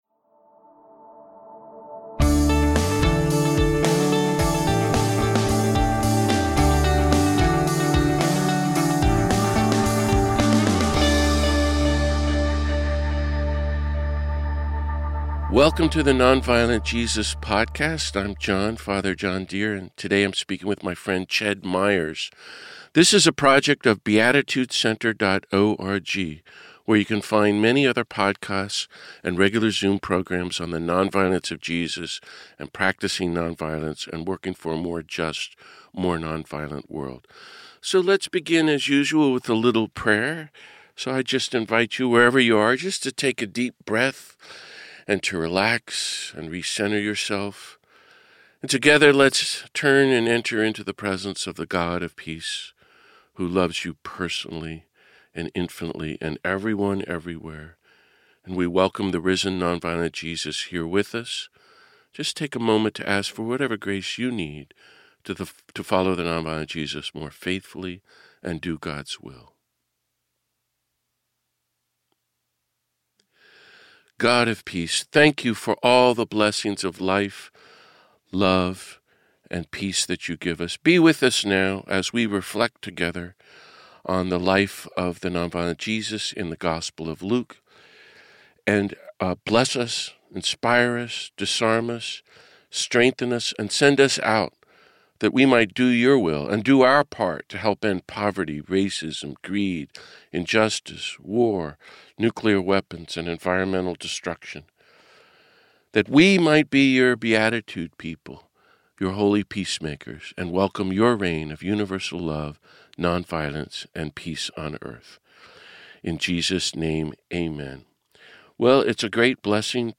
He begins our conversation by sharing his journey to radical Christianity through the Catholic Worker movement and our mentor Daniel Berrigan, and then we turn to Luke.